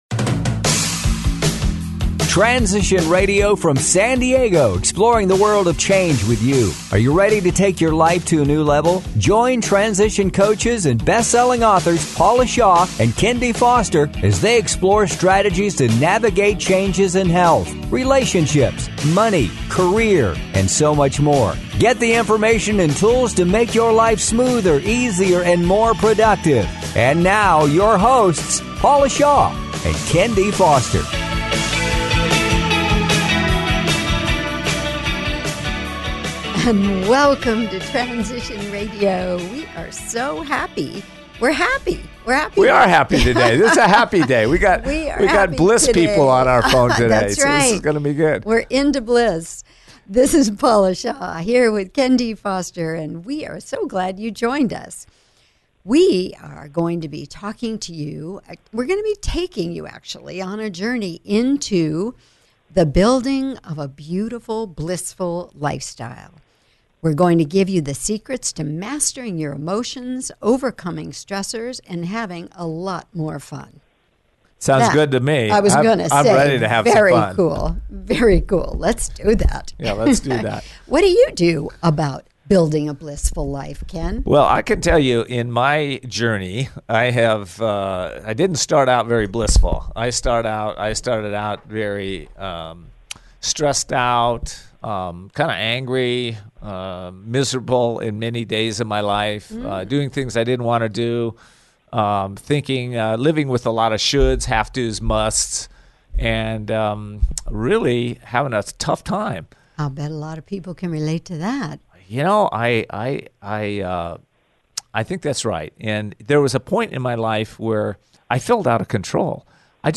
Transition Radio Show